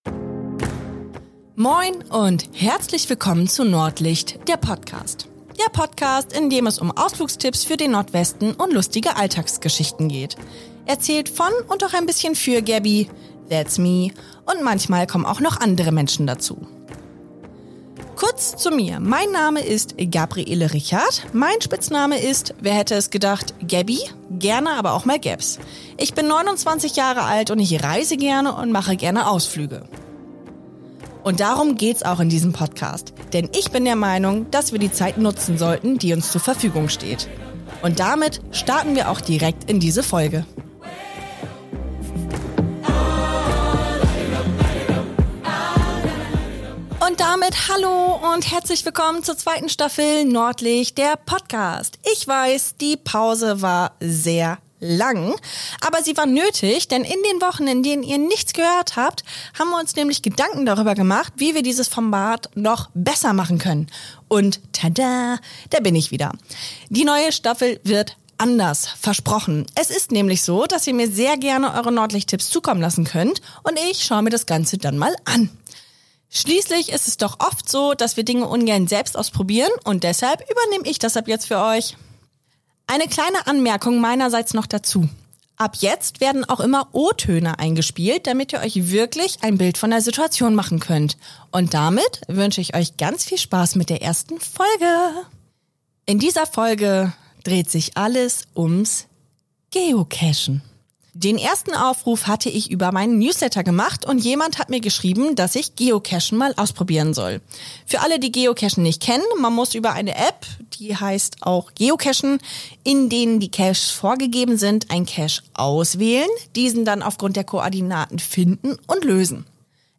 In dieser Folge geht es ums Geocachen. Dieser Ausflug war eine wilde Achterbahnfahrt der Gefühle und des Windes, aber hört selbst.